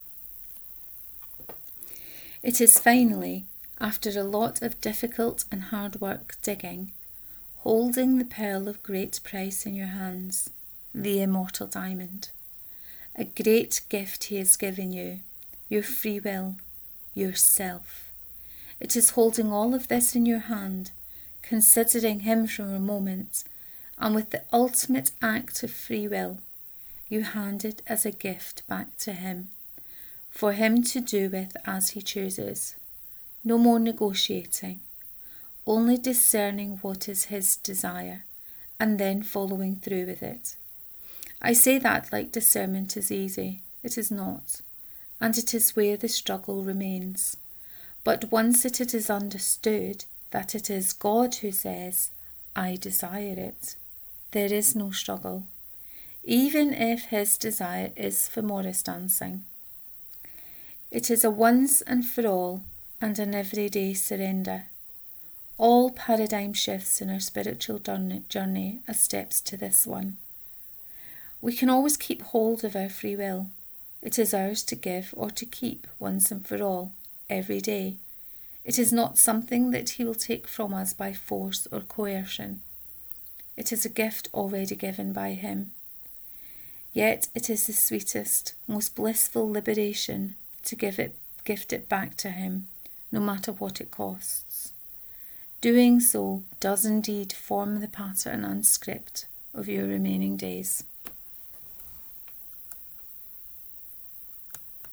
The Paradigm Shift 5 : Reading of this post